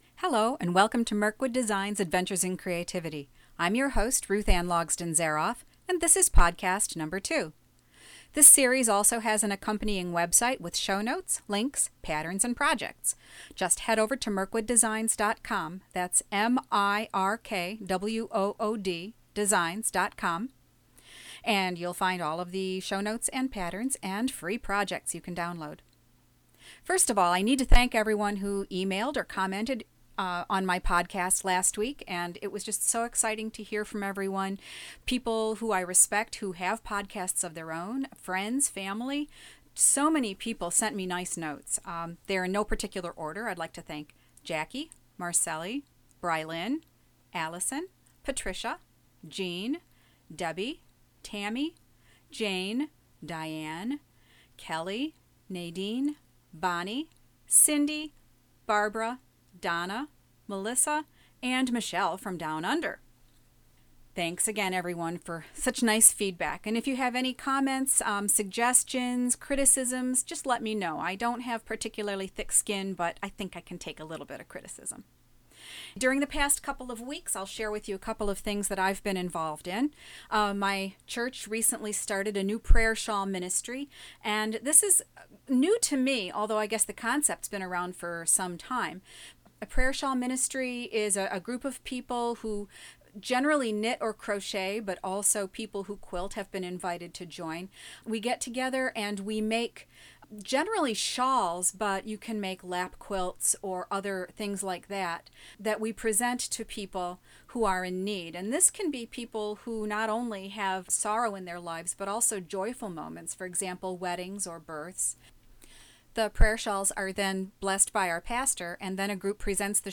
I kept thinking all through the podcast what a talented lady you are....and then you ended it with the beautiful singing.
INTRODUCTION OK, so after listening to this podcast I realized a couple of things: I need to remember to use my pop filter, and I need to talk more to flesh out a couple of segments, especially the descriptions of the various projects.